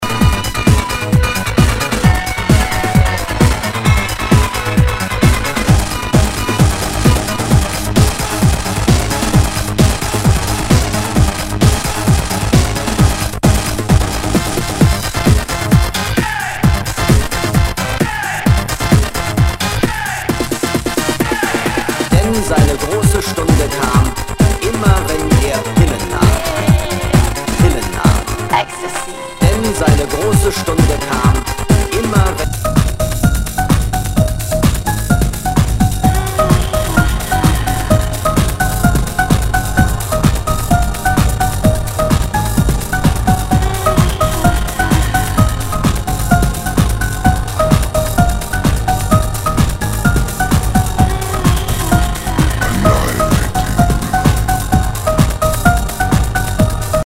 HOUSE/TECHNO/ELECTRO
テクノ・クラシック！